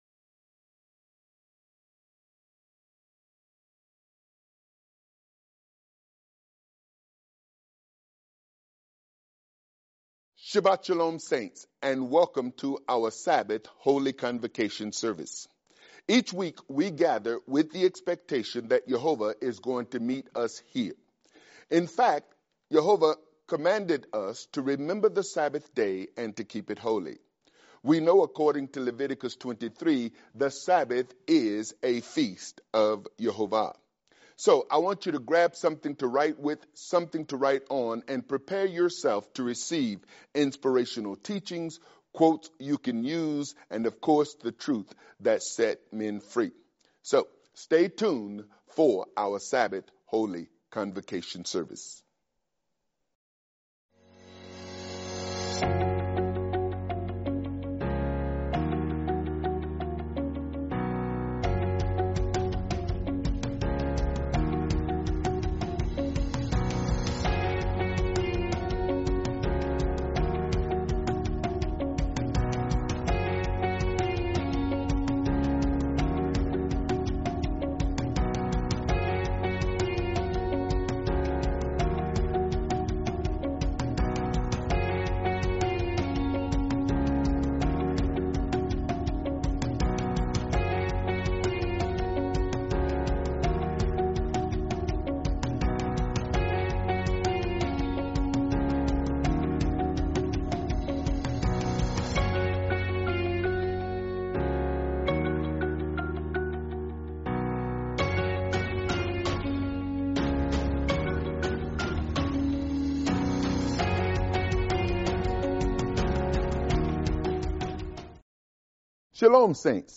Teaching